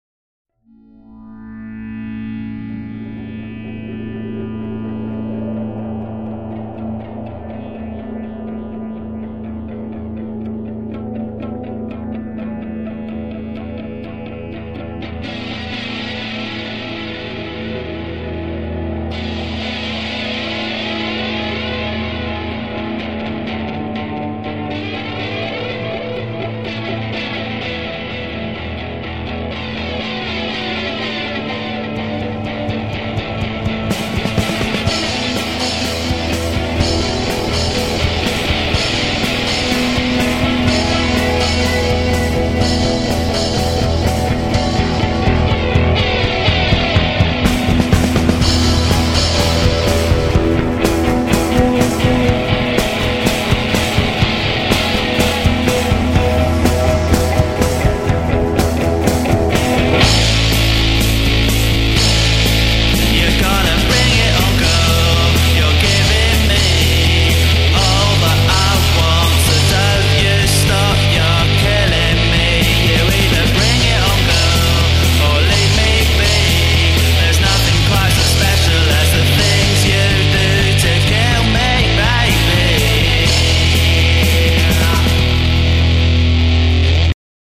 Unfortunately, due to time constraints, it never got beyond the draft stage. Most instruments and the vocal were recorded in a single take, and then given a rudimentary mix - just enough to give the boys a feel for the tune and decide whether it was suitable - and this was the version that ended up in the show!